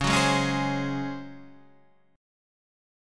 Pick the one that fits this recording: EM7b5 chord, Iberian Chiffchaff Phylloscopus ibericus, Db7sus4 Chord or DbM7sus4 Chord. DbM7sus4 Chord